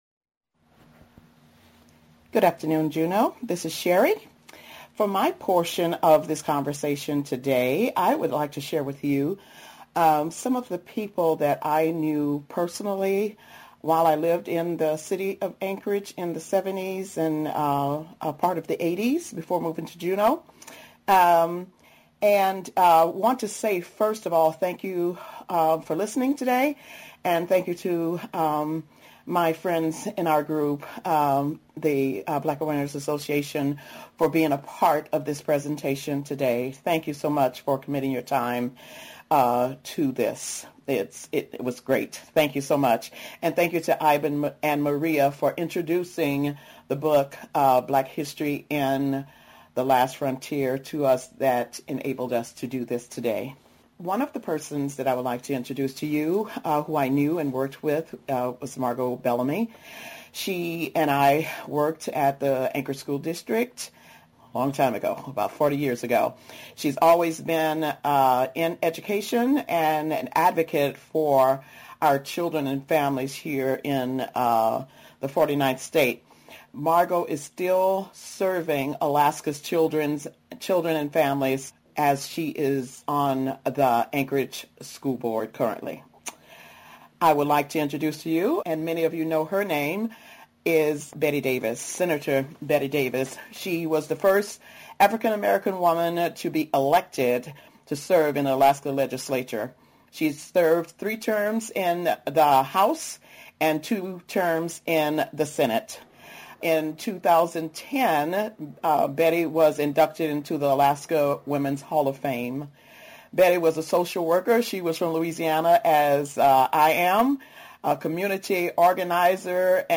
On today’s show, we will continue our month-long series featuring conversations with the Juneau Black Awareness Association, Thursdays on Juneau Afternoon. Please join us as they spend the hour in discussion and dialogue about themselves, their organization, and much more.